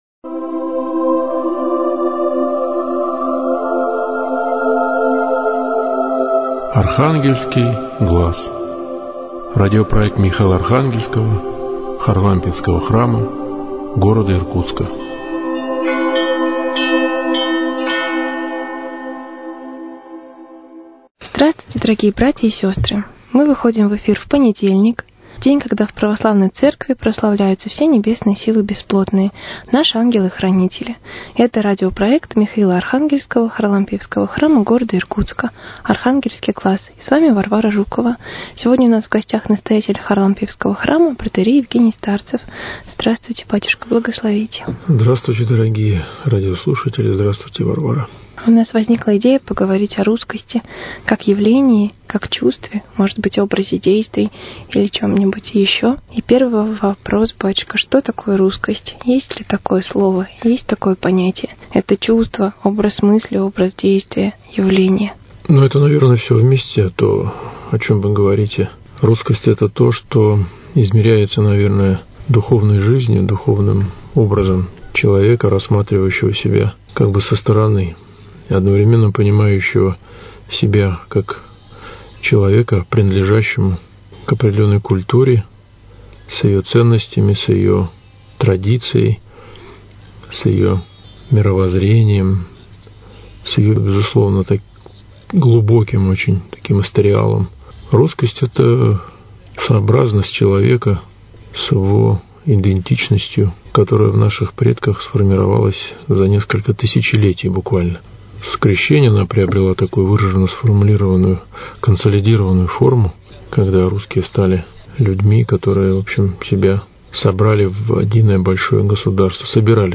В этом выпуске беседа